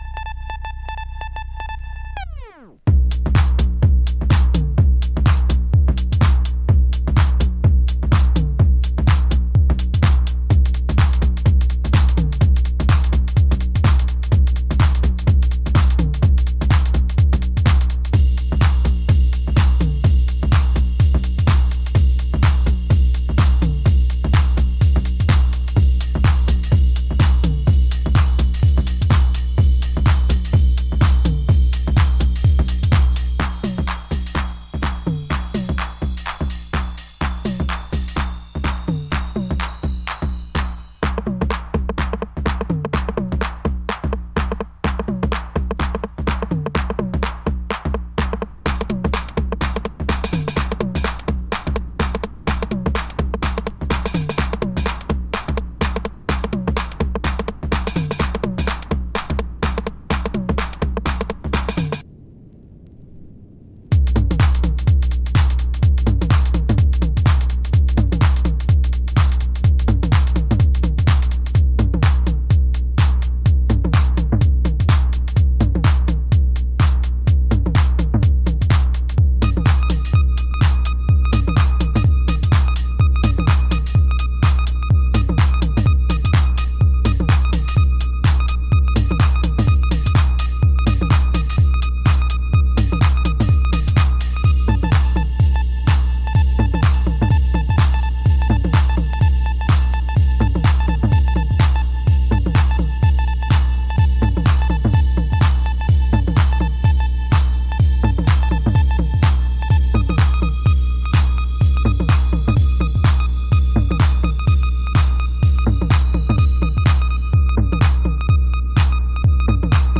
冷酷な近未来のストリートを想像してしまうミニマル・ベースライン・ハウス